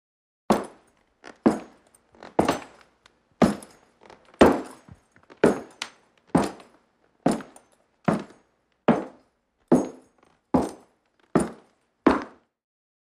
FootstepsBootsWood PE770102
FOLEY FOOTSTEPS BOOTS: INT: Western boots & spurs on wood, medium walk, echo.